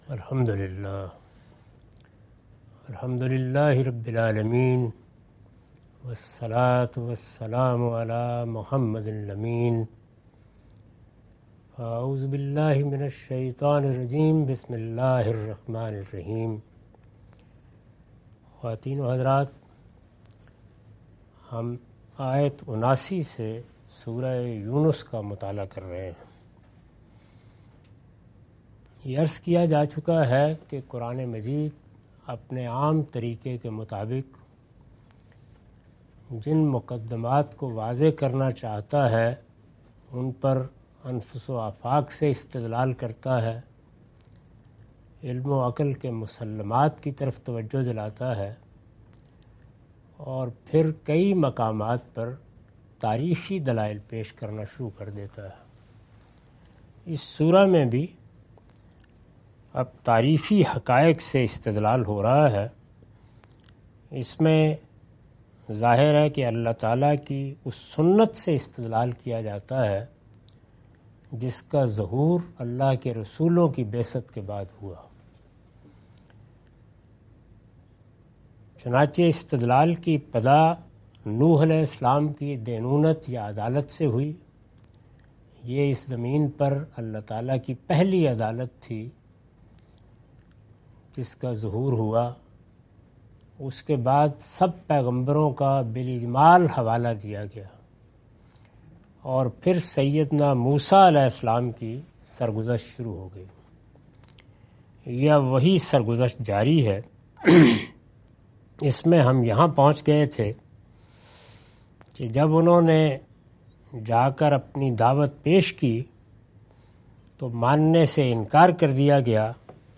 Surah Yunus- A lecture of Tafseer-ul-Quran – Al-Bayan by Javed Ahmad Ghamidi. Commentary and explanation of verses 79-83.